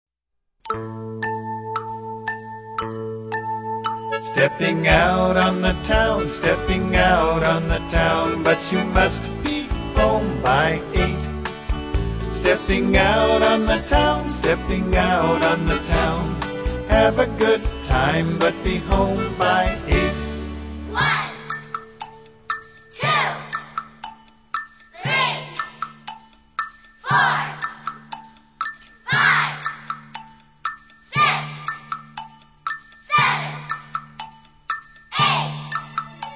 A Fun Movement - Counting Song